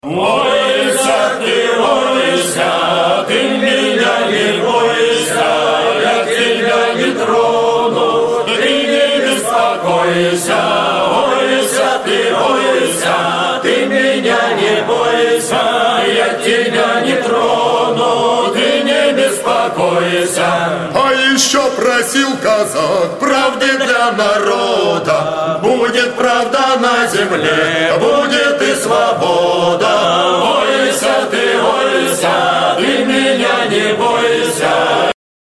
Категория: Саундтреки | Дата: 28.11.2012|